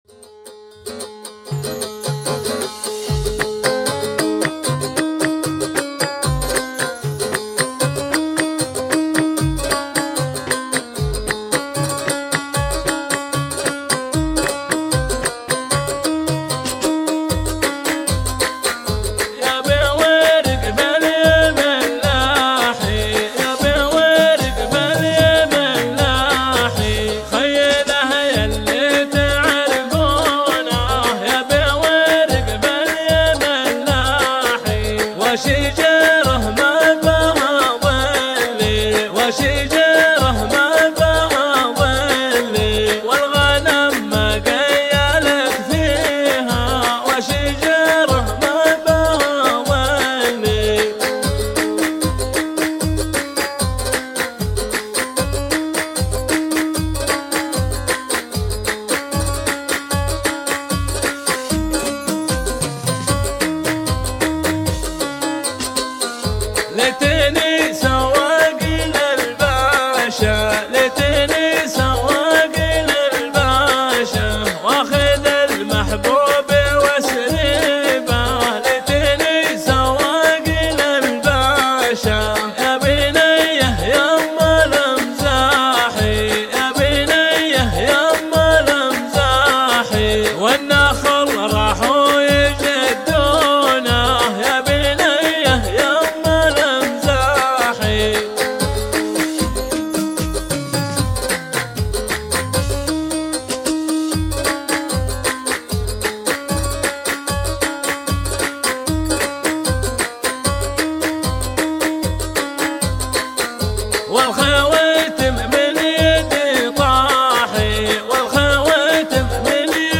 هذا الخبيتي
الصوت مركب على الفيديو